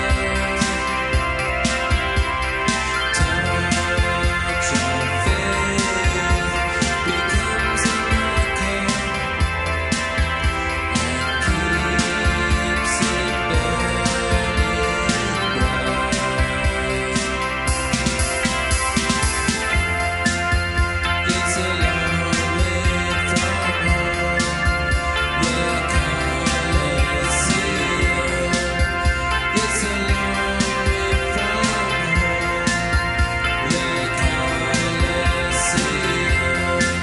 Recorded at La Maison (Bondi) except "At The Castle"